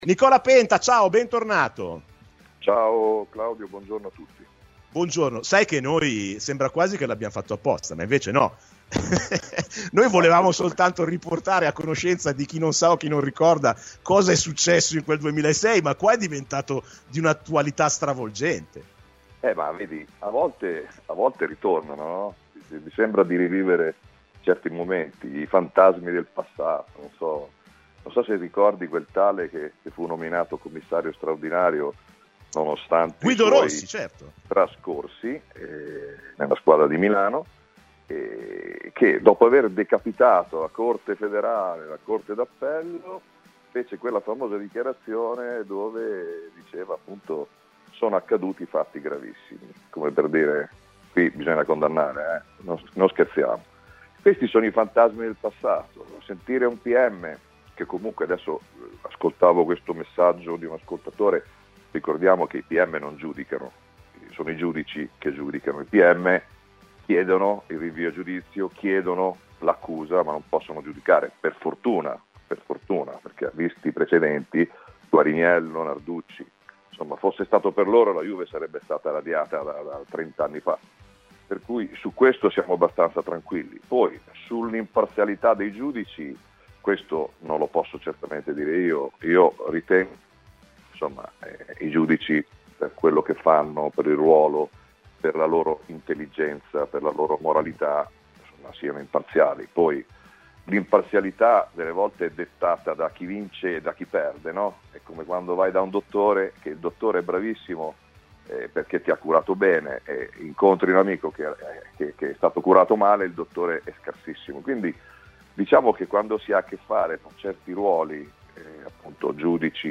Decisivo fu però il fallo di Toldo su Zalayeta che al 20' della ripresa valse il rigore del raddoppio della Juve ma nell'occasione non costò un rosso solare al portiere dell'Inter . La serie di telefonate che ascolterete oggi riguardano tutte quella partita.